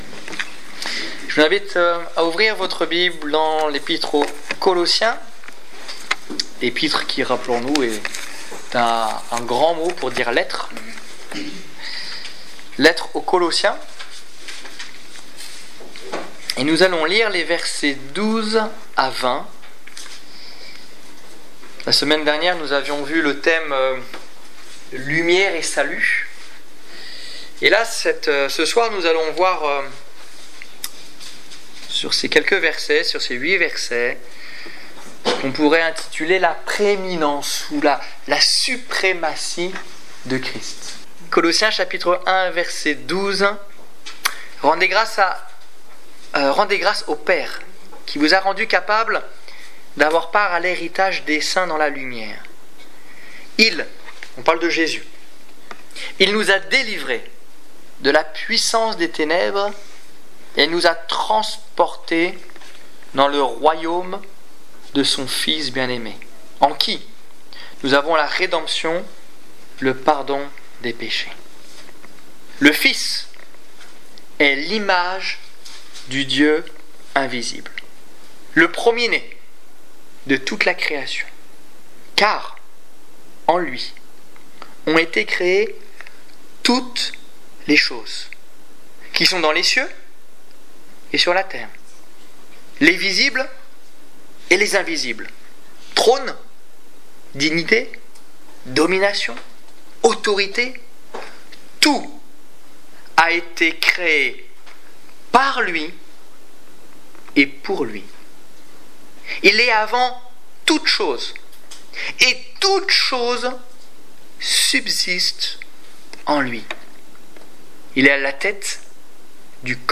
Étude biblique du 1 octobre 2014